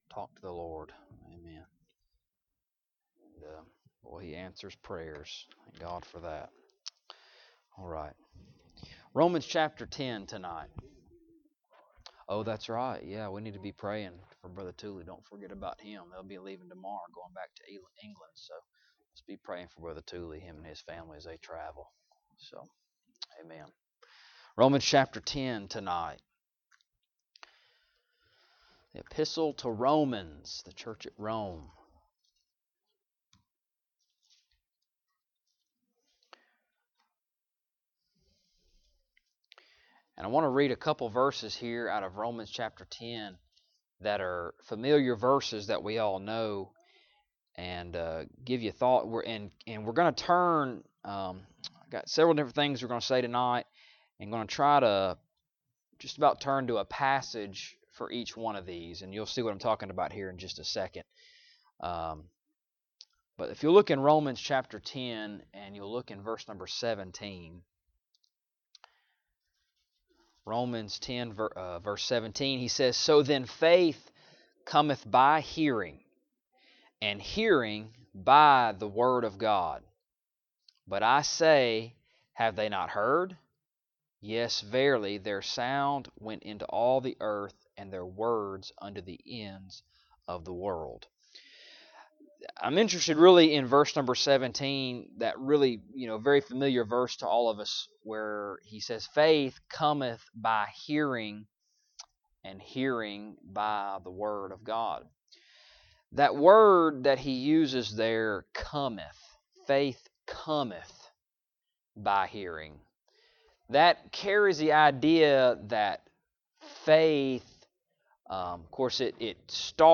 Romans 10:17-18 Service Type: Wednesday Evening Topics